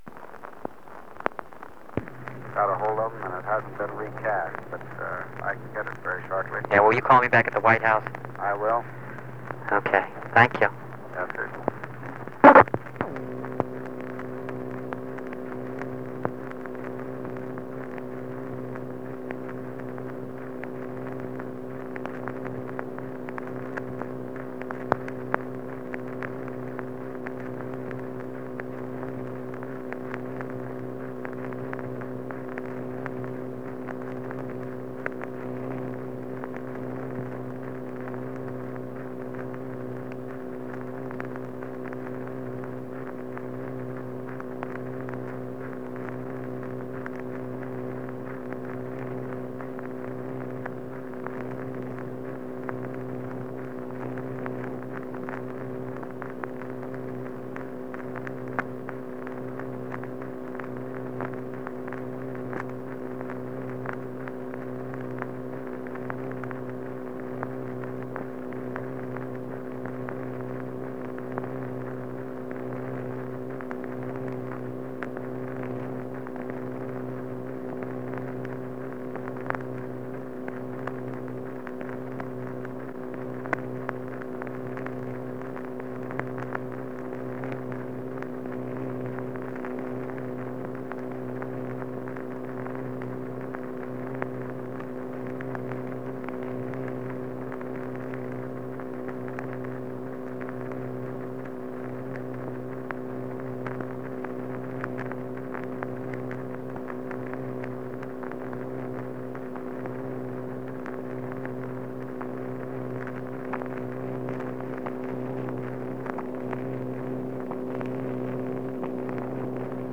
Conversation between Robert Kennedy and Creighton Abrams (cont.)
Secret White House Tapes | John F. Kennedy Presidency Conversation between Robert Kennedy and Creighton Abrams (cont.)